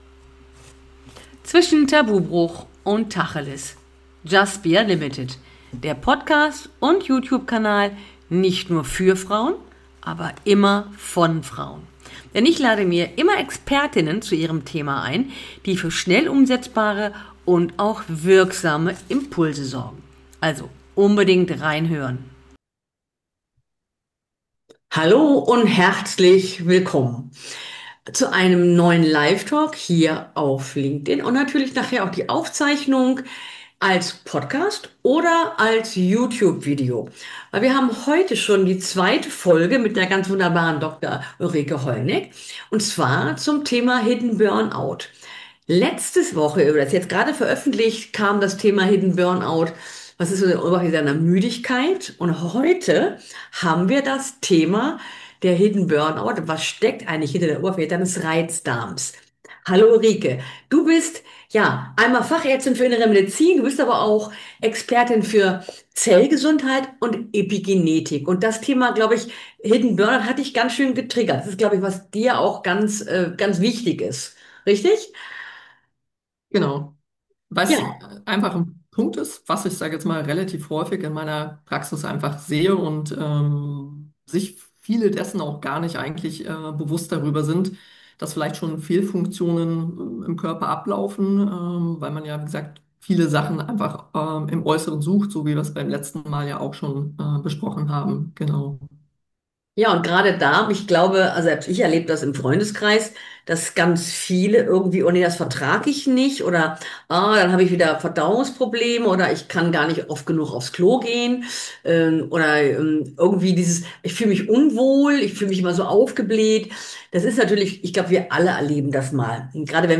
Nachdem wir im ersten Teil über das Thema Hidden Burn Out und Müdigkeit gesprochen haben, widmen wir uns in diesem Live Talk dem Thema Darm und Darmgesundheit.